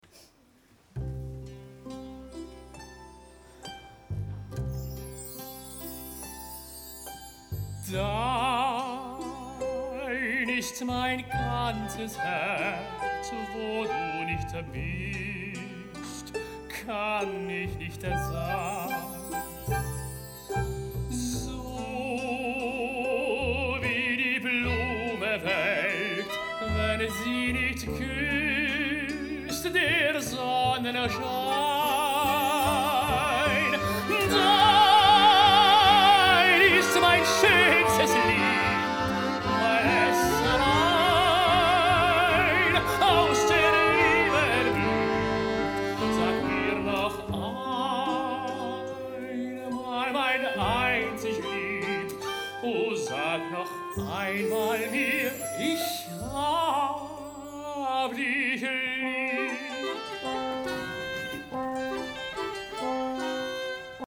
Eine Hybridoperette mit der Musicbanda Franui (2024)